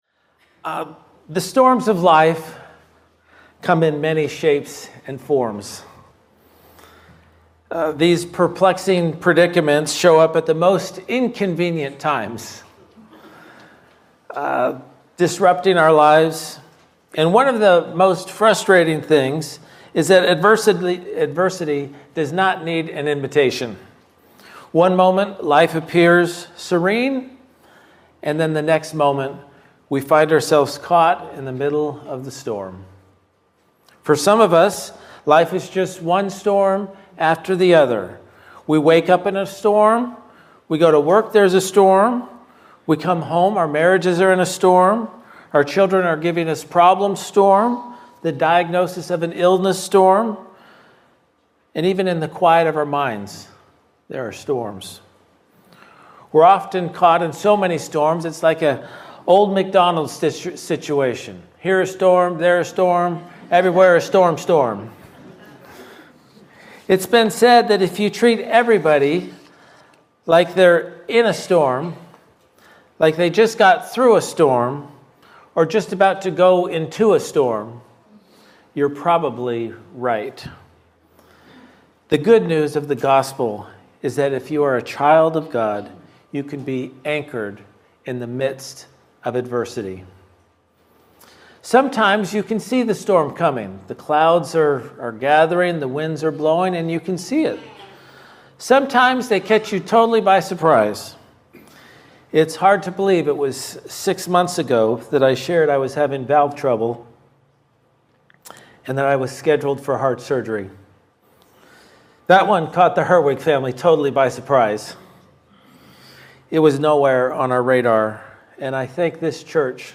Guest Speaker, Standalone Sermon